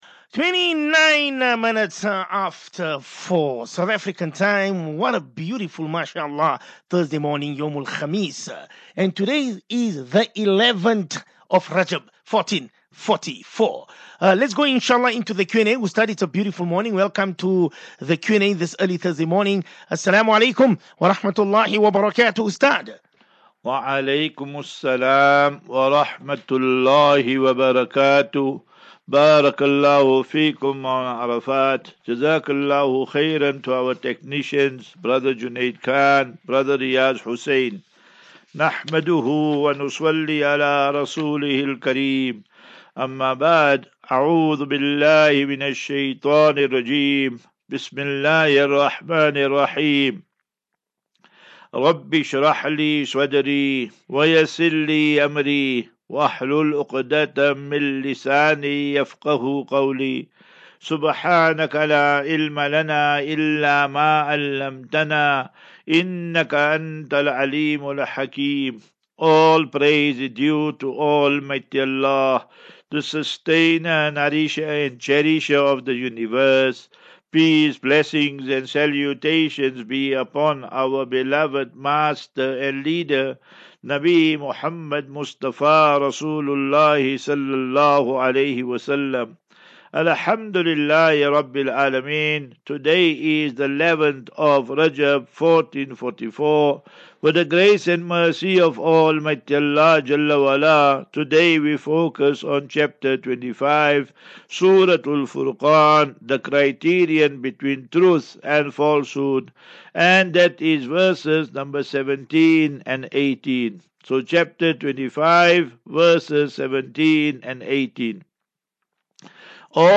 Naseeha and Q and A